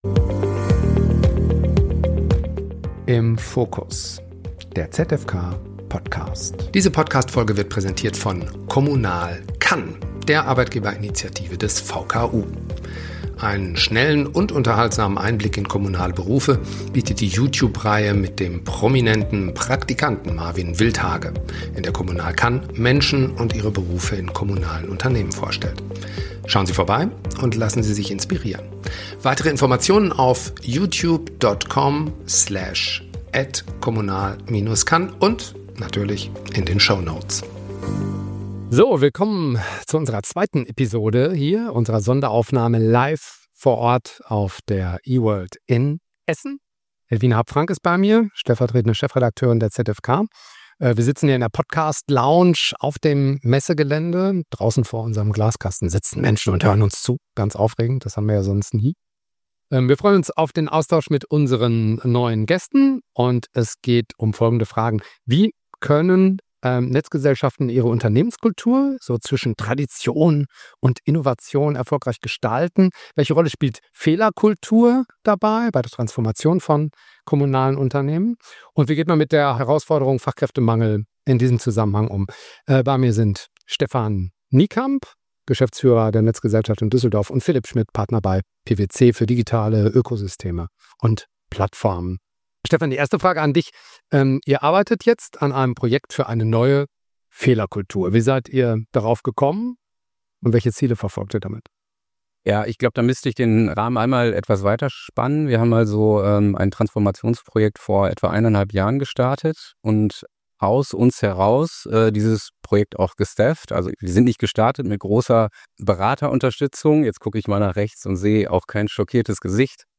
Fehlerkultur in der Transformation bei der Netzgesellschaft Düsseldorf - Live auf der E-world – Teil 2 ~ Im Fokus - Der ZFK-Podcast Podcast